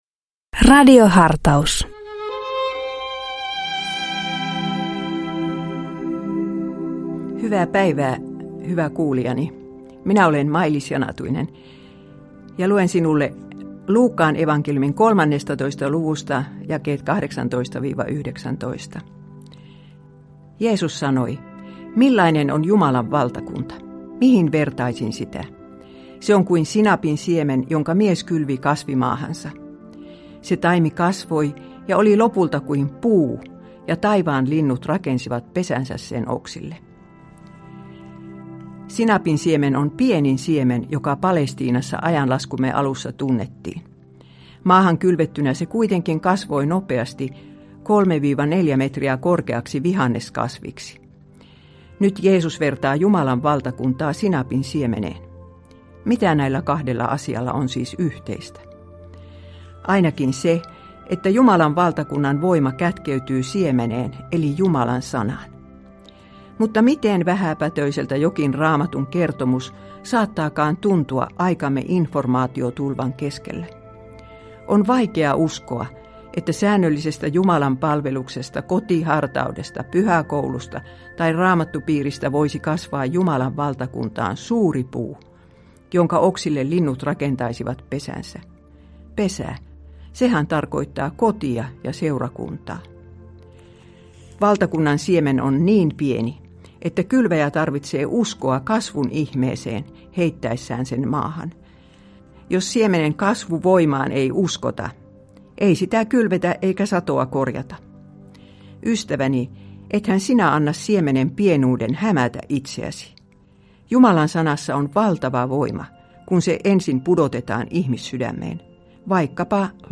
Radio Dei lähettää FM-taajuuksillaan radiohartauden joka arkiaamu kello 7.50. Radiohartaus kuullaan uusintana iltapäivällä kello 16.50. Radio Dein radiohartauksien pitäjinä kuullaan laajaa kirjoa kirkon työntekijöitä sekä maallikoita, jotka tuntevat radioilmaisun omakseen.